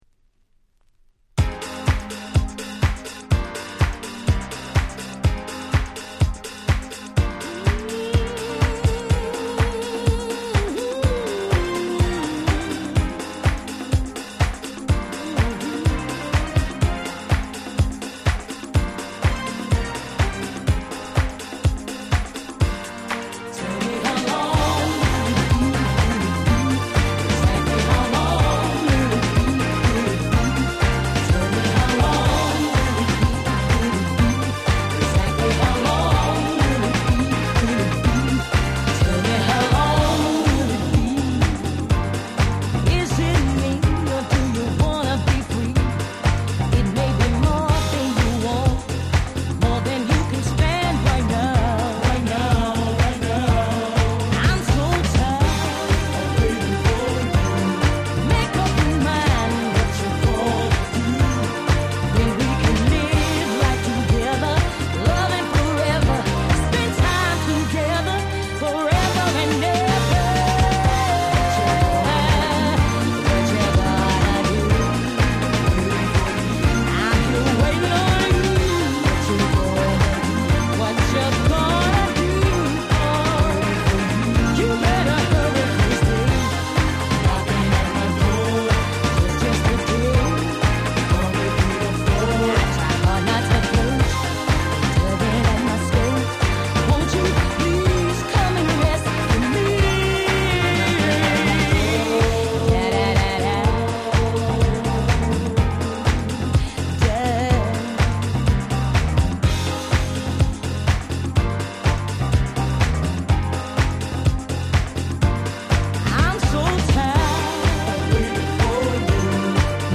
04' Nice Japanese House/R&B !!